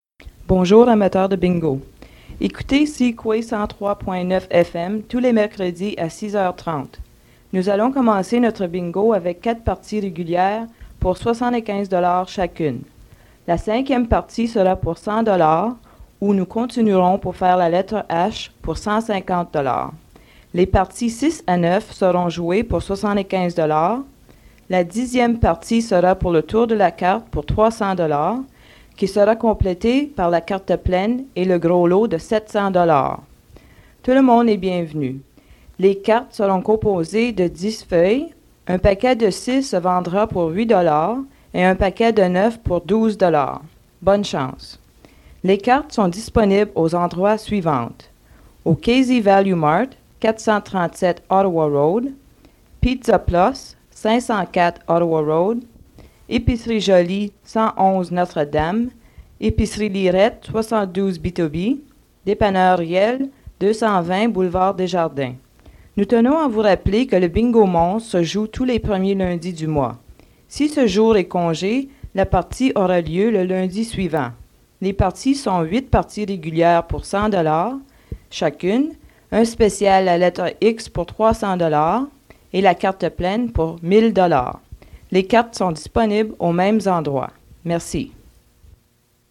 Fait partie de Bingo announcement